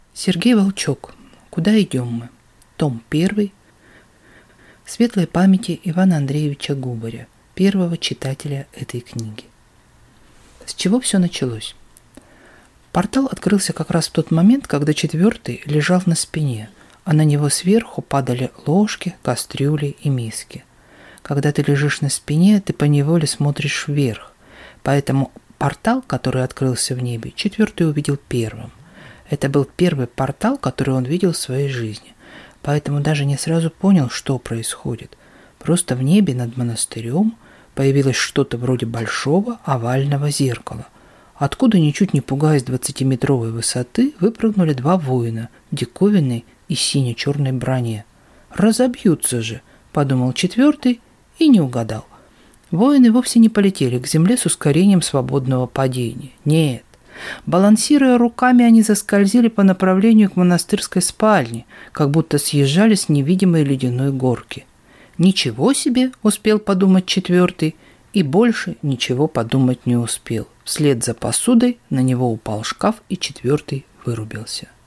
Аудиокнига Куда идем мы… – 1 | Библиотека аудиокниг